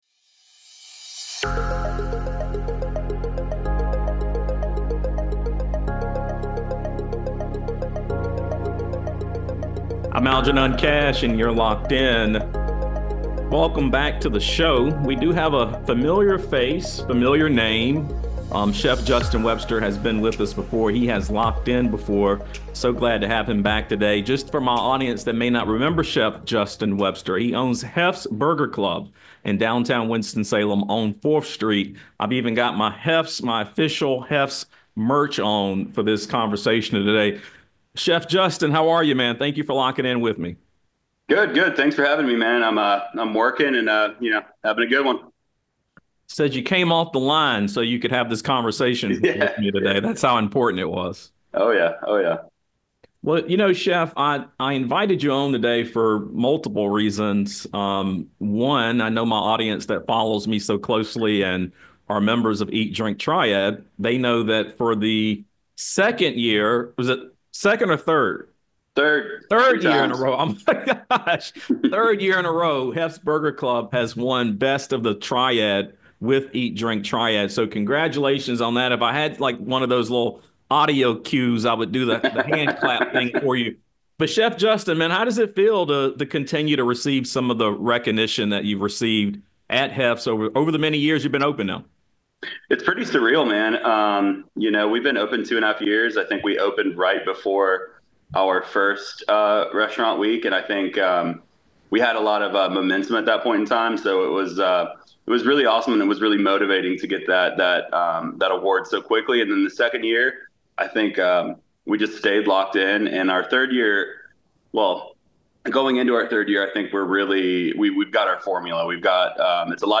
With big chains dominating the market and customer loyalty harder to earn, what does it take for a small restaurant to thrive? Tune in for an eye-opening conversation about the realities of running an independent eatery in today’s economy!